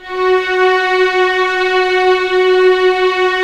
Index of /90_sSampleCDs/Roland LCDP13 String Sections/STR_Violins IV/STR_Vls7 f wh%